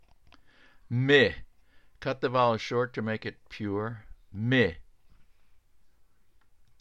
Polish Words -- Baritone Voice
my (MIH)
NOTE: The /Y/ is a pure vowel and pronounced like the [Y] in "myth".